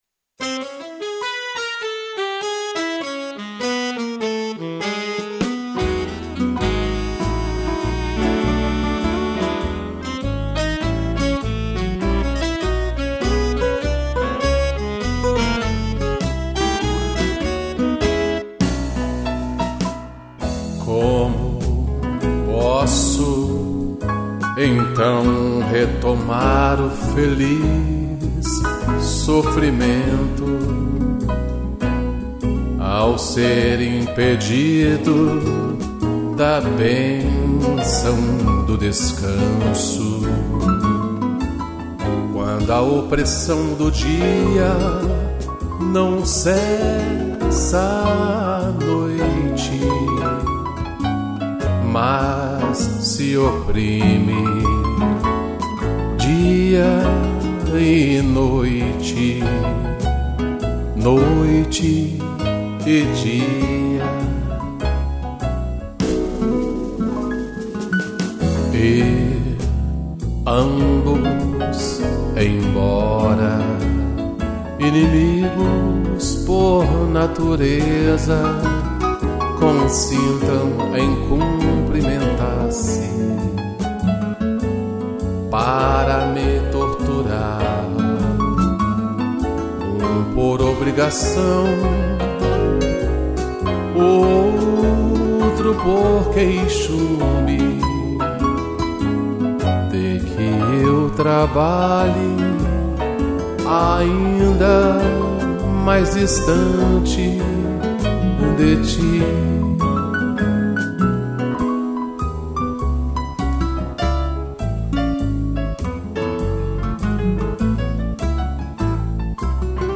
voz e violão
piano